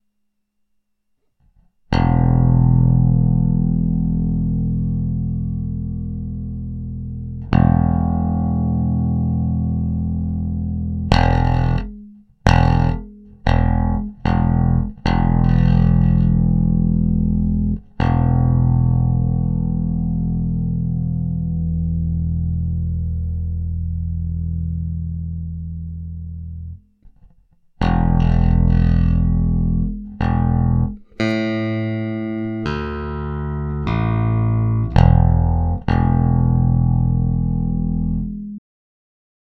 Ich hab mal einen Basston aufgenommen. Einmal ohne und einmal mit dem Amplitube VST.
Ich finde, sobald Amplitube drauf ist, klingt es weitaus schlechter.